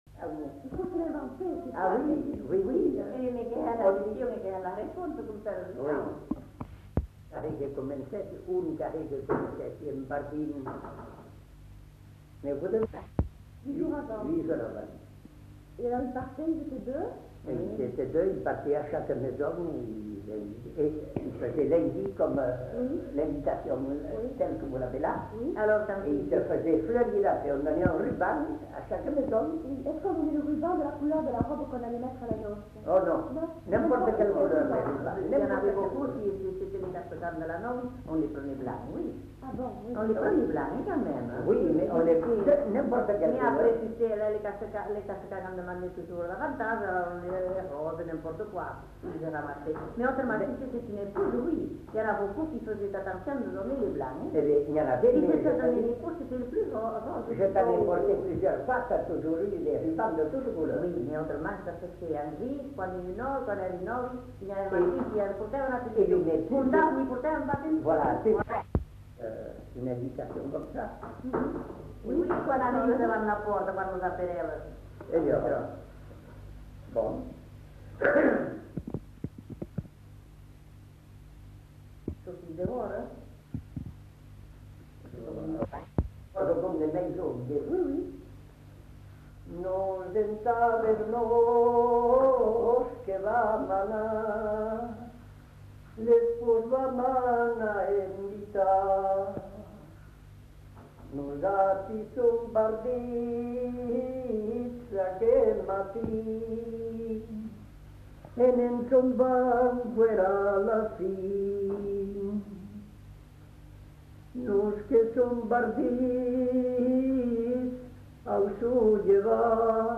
Lieu : Haut-Mauco
Genre : chant
Type de voix : voix d'homme
Production du son : chanté